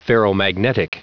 Prononciation du mot ferromagnetic en anglais (fichier audio)
Prononciation du mot : ferromagnetic